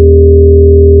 plt.title('noisy hum')
plt.title('resulting signal with noise')